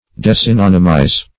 Search Result for " desynonymize" : The Collaborative International Dictionary of English v.0.48: Desynonymize \De`syn*on"y*mize\, v. t. To deprive of synonymous character; to discriminate in use; -- applied to words which have been employed as synonyms.